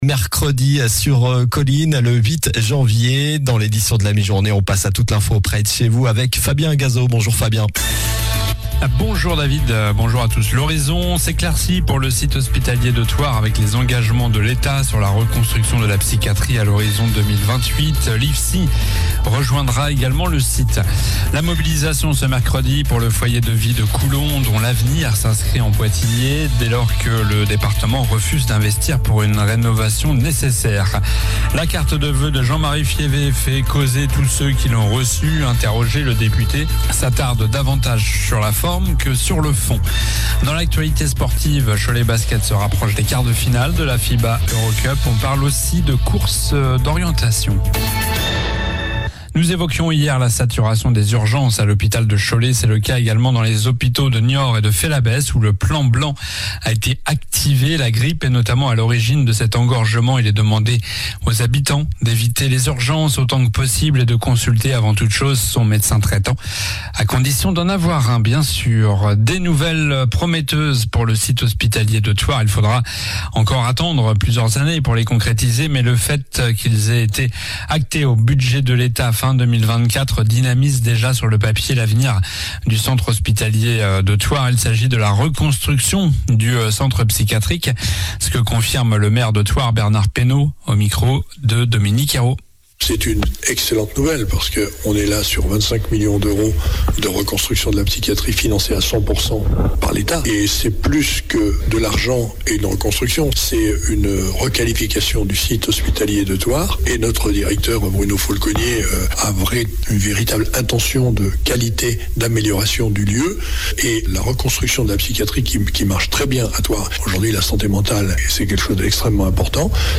Journal du mercredi 08 janvier (midi)